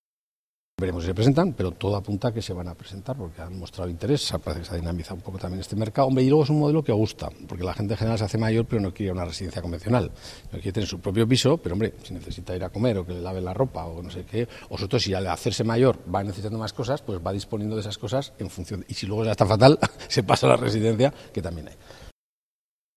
El vicepresidente de Zaragoza Vivienda, Jerónimo Blasco, ha explicado esta iniciativa y sus antecedentes: